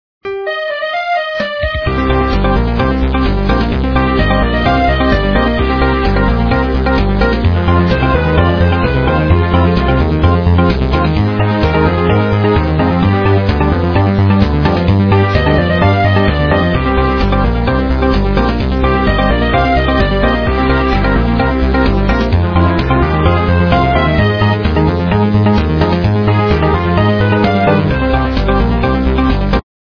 полифоническую мелодию